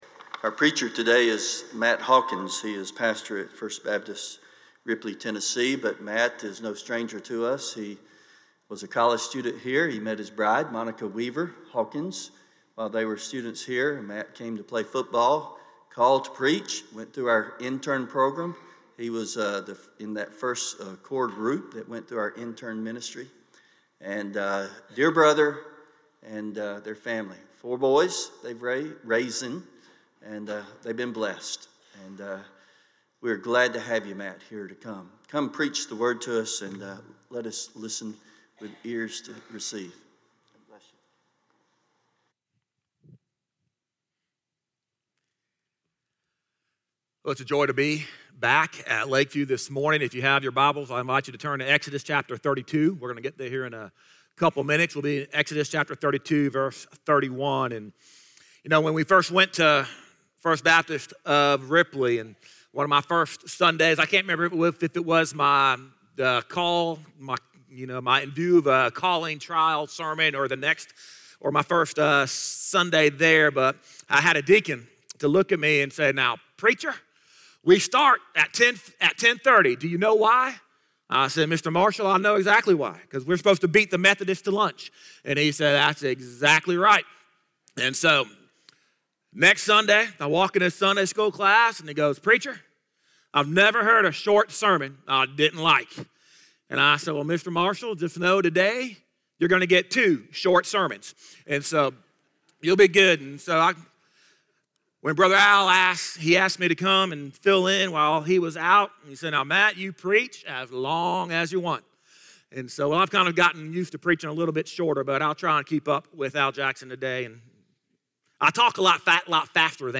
Stand Alone Sermons
Service Type: Sunday Morning Topics: Difficulty , Hope